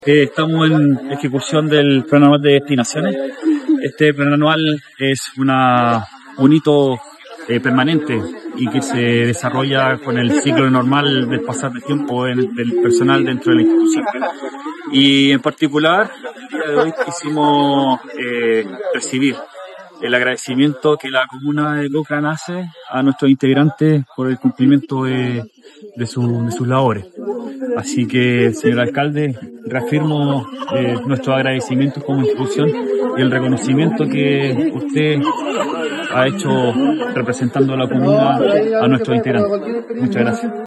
Cochrane-. En una solemne ceremonia realizada en la comuna de Cochrane, se entregó la medalla «Ilustre Municipalidad de Cochrane» como reconocimiento al destacado desempeño y compromiso profesional de 23 miembros de la Compañía Andina Divisionaria N.º 20 «Cochrane».